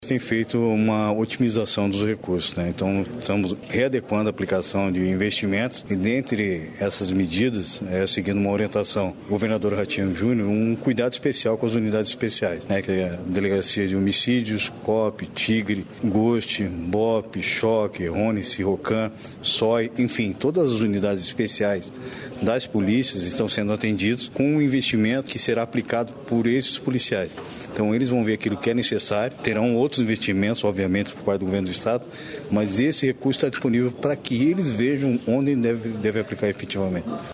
Sonora do secretário estadual da Segurança Pública, coronel Hudson Teixeira, sobre os repasses para as unidades especiais de segurança